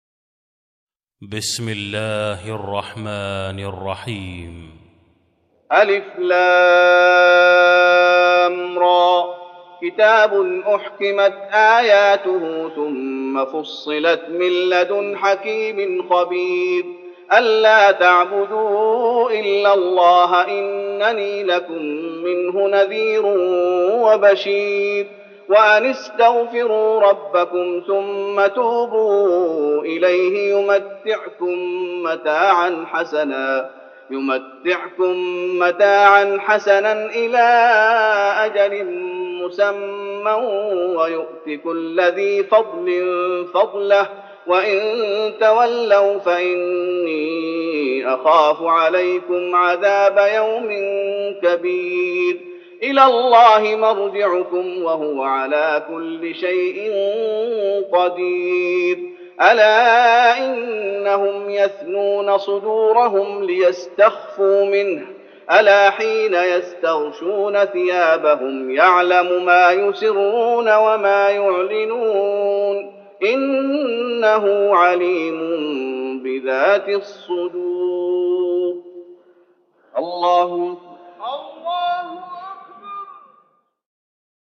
تراويح رمضان 1413هـ من سورة هود (1-5) Taraweeh Ramadan 1413H from Surah Hud > تراويح الشيخ محمد أيوب بالنبوي 1413 🕌 > التراويح - تلاوات الحرمين